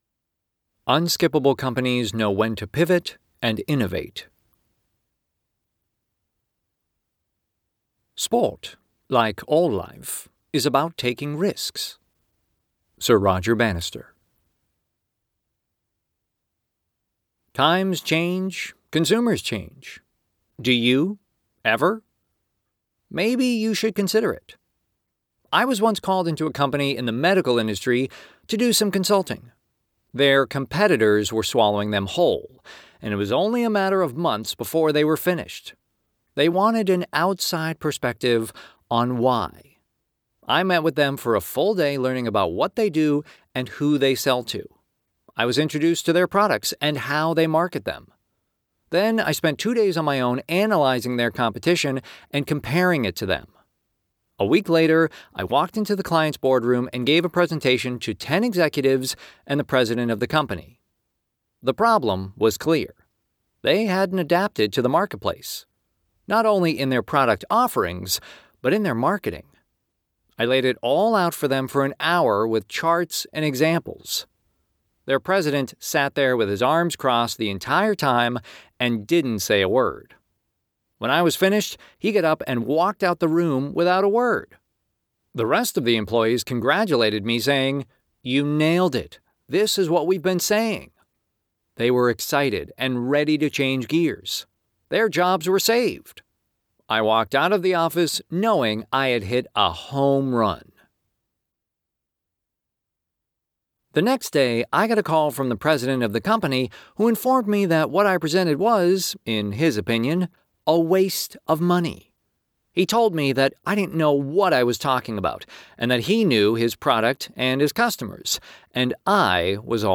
The following is an audio excerpt from the audiobook version of Your Journey to Becoming Unskippable.